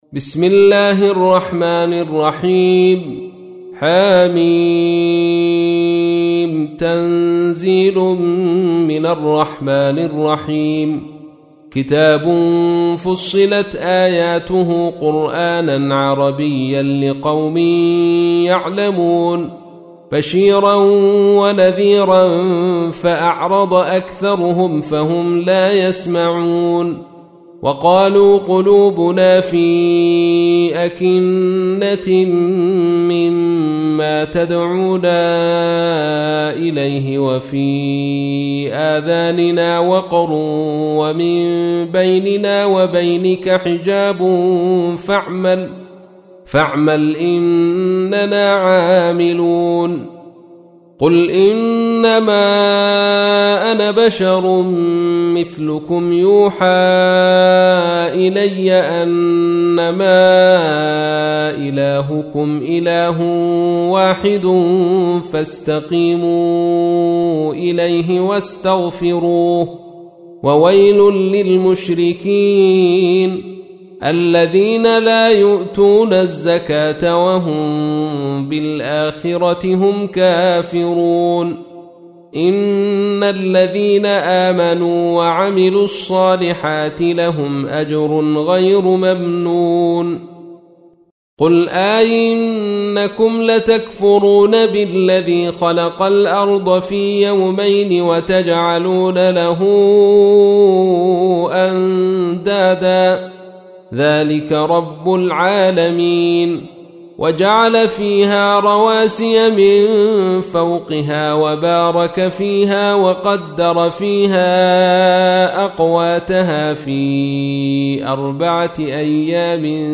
تحميل : 41. سورة فصلت / القارئ عبد الرشيد صوفي / القرآن الكريم / موقع يا حسين